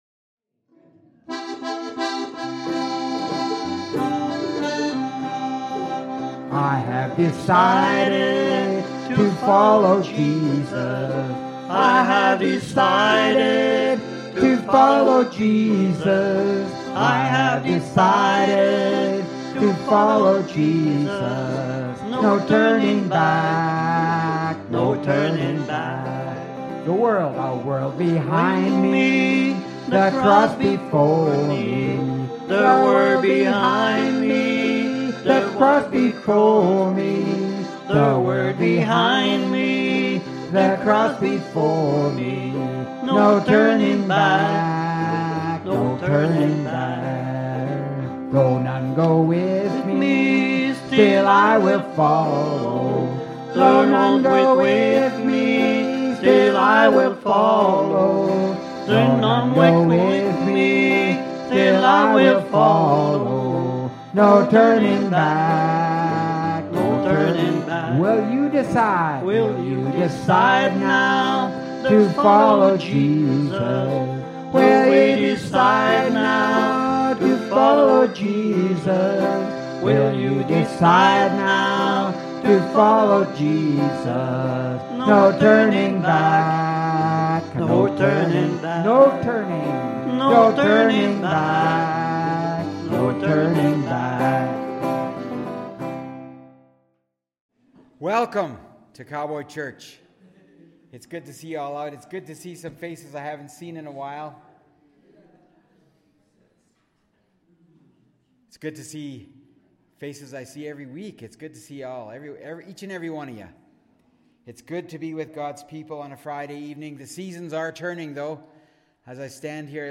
(part 1) MP3 SUBSCRIBE on iTunes(Podcast) Notes Discussion Sermons in this Series Luke 24:44, Genesis 1: 2, Zechariah 4: 6, Zechariah 11: 17, Mark 16: 15 Loading Discusson...